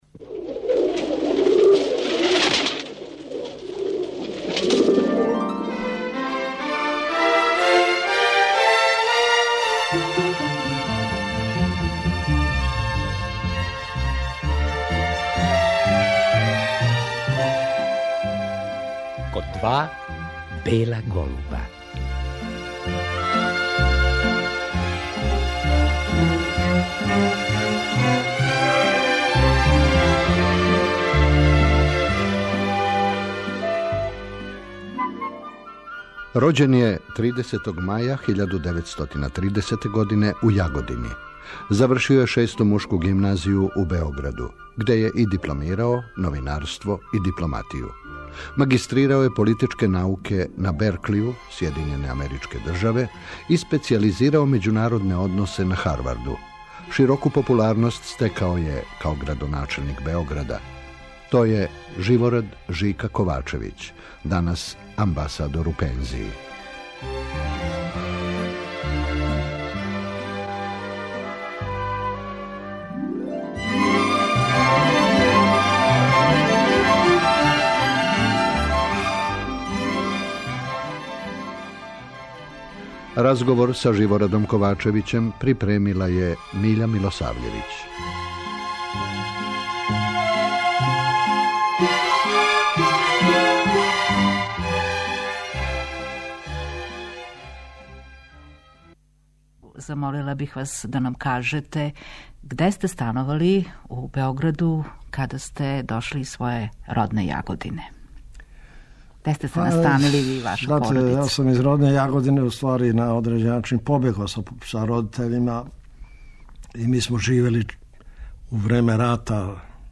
Био је активан у друштву и политици до данашњих дана, а гост Радио Београда био је веома често, између осталог и 2007. године, када је снимљен интервју из кога ћемо вечерас чути одломке.
У интервјуу из 2007. Живорад Ковачевић говори о успоменама из живота и рада.